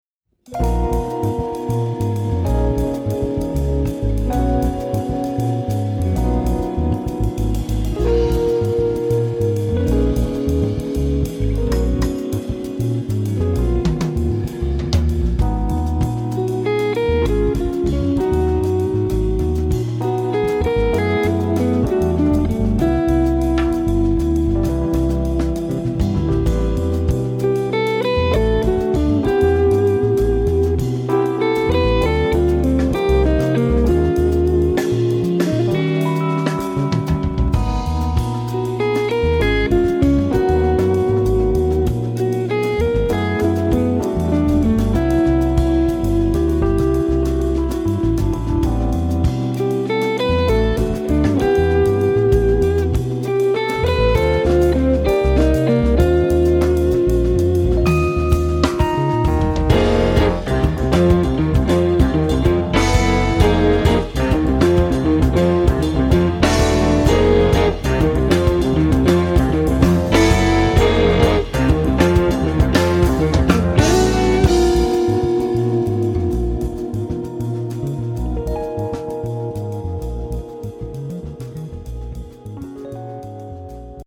guitar
keyboards
bass
drums Share